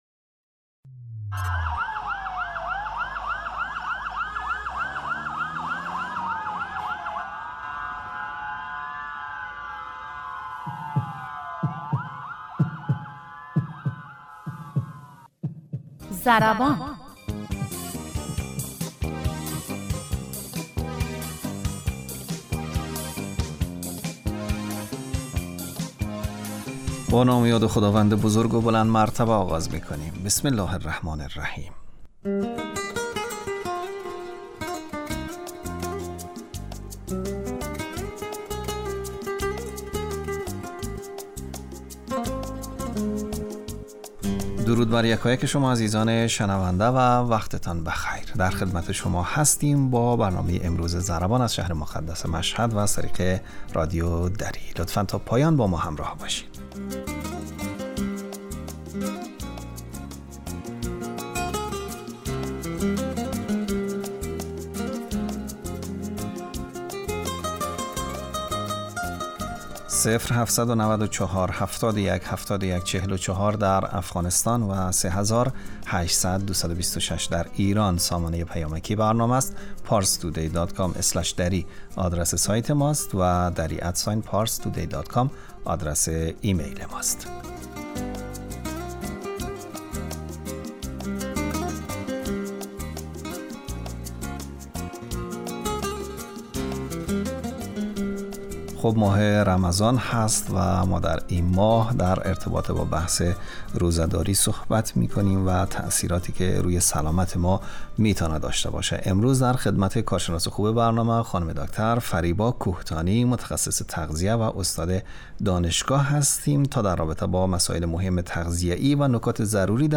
رادیو